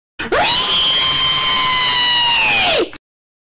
Reeeeeeeeee Meme Effect Sound sound effects free download